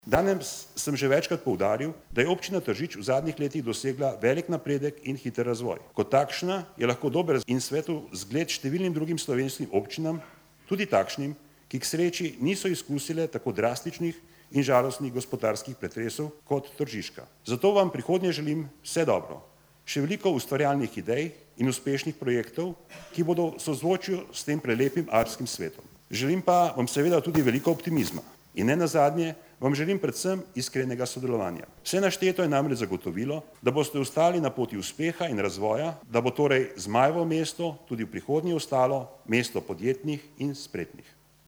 76743_izjavapredsednikadrzavnegazborarsdr.milanabrgleza.mp3